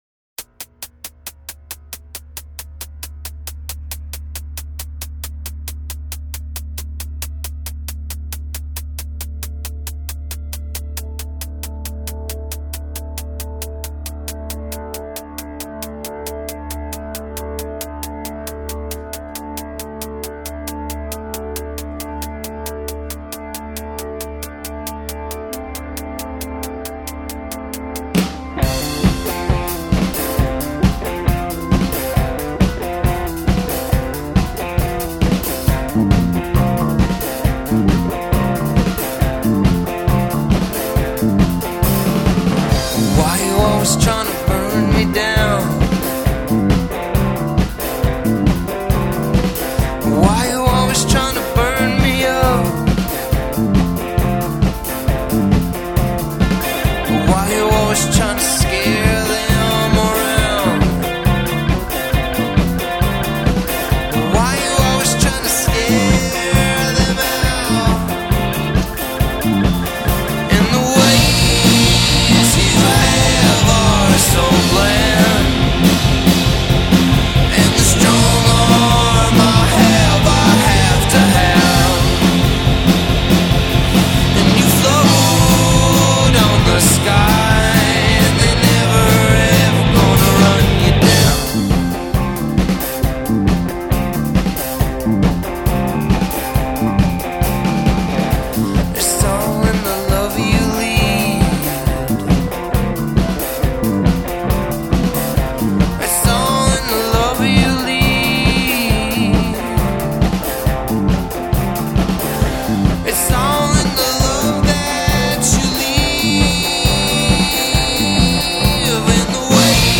guitar and vocals
violin